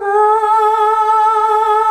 AAAAH   A.wav